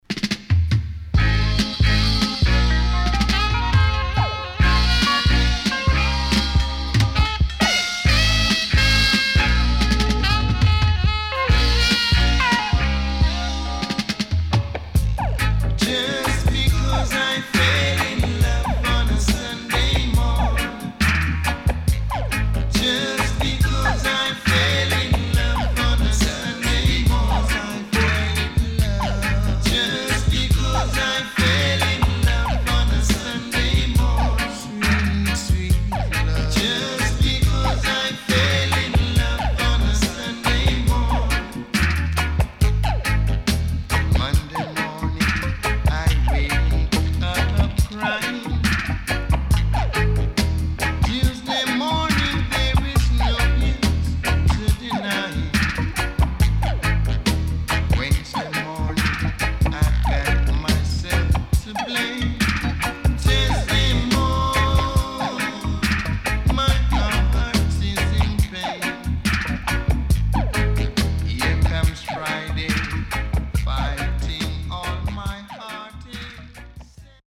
CONDITION SIDE A:VG(OK)〜VG+
Good Roots Vocal
SIDE A:所々チリノイズがあり、少しプチノイズ入ります。